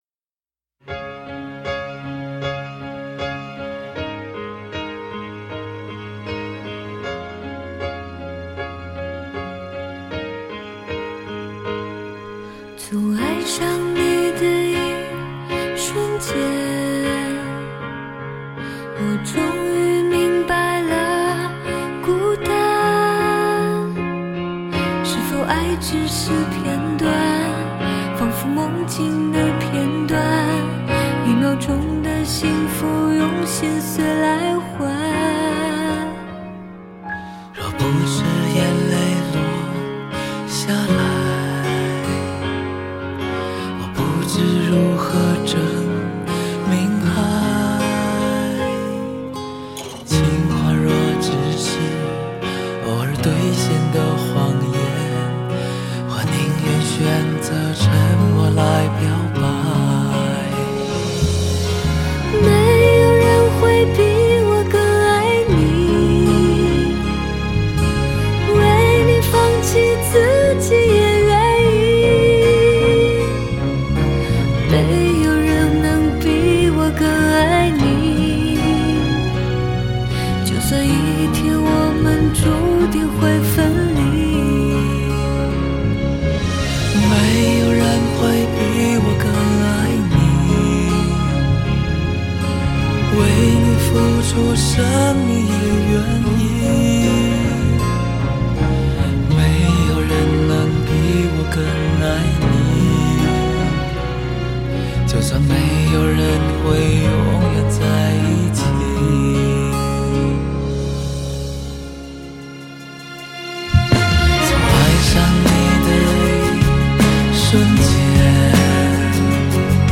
风格：流行/Pop